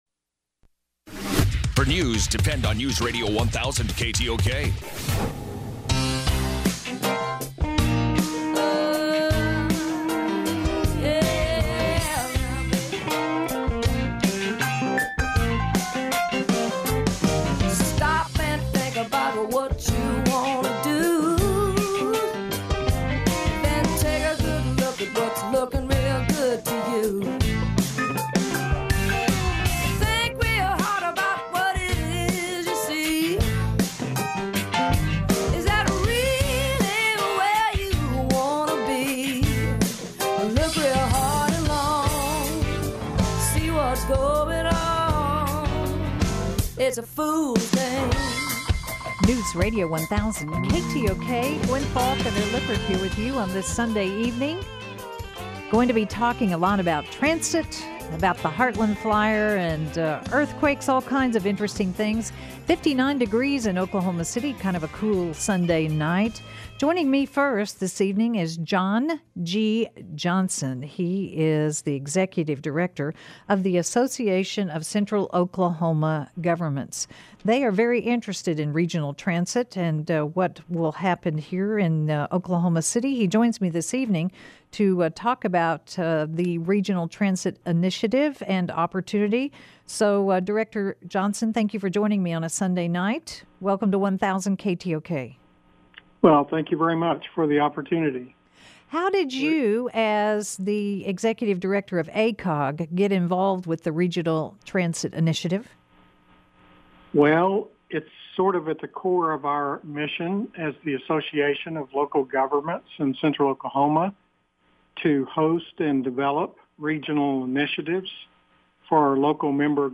KTOK Interview